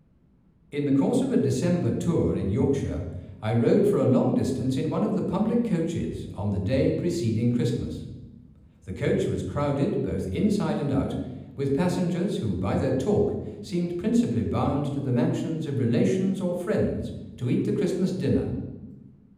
The background sound level in the room is quite low, with just some noticeable noise from the air handling system and the four ceiling-mounted digital projectors, but the intelligibility of spoken word is not good.
The measured reverb times of 2 seconds at low frequencies gives a "boomy" quality to spoken words.
Playback of spoken sentences recorded in Harrison 123
Harrison_123_speech1.wav